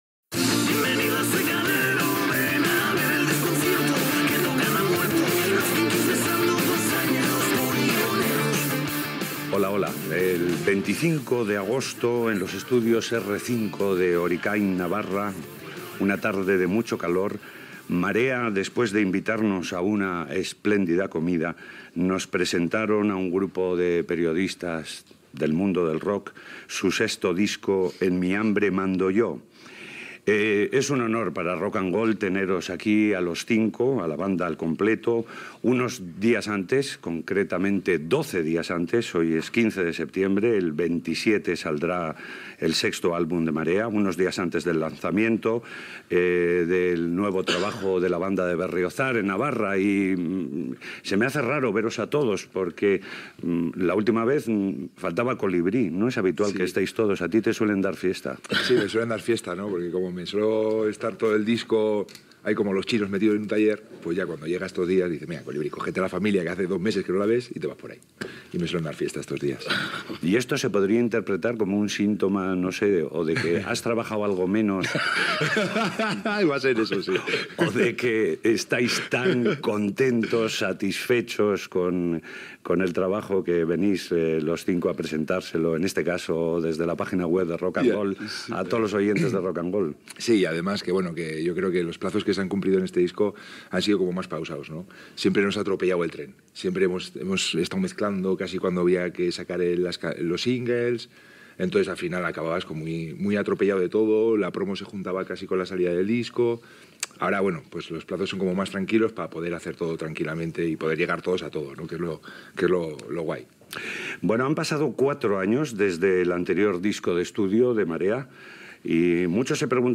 Entrevista al grup Marea abans de la presentació del su disc "En mi hambre mando yo"